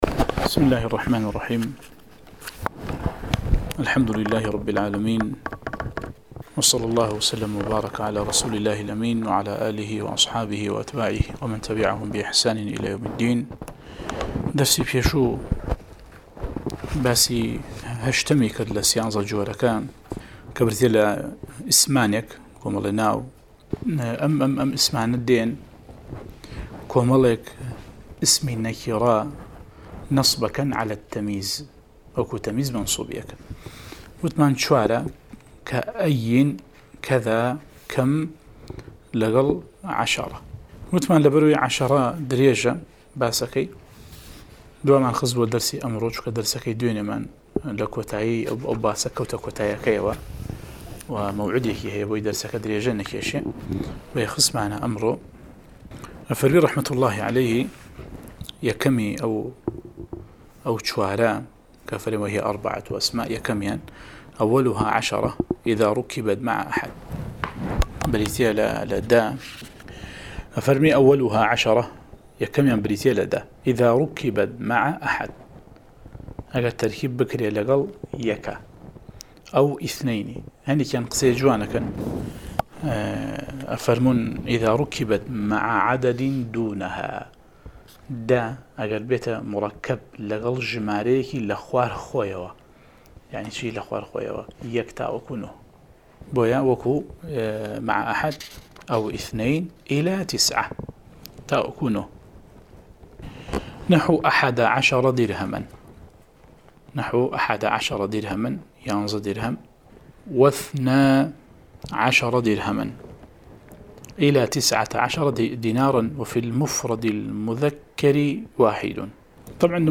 10 ـ شەرحی العوامل المائة، (عوامل الجرجانی) (نوێ) وانەی دەنگی: